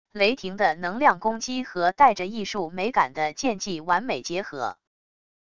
雷霆的能量攻击和带着艺术美感的剑技完美结合wav音频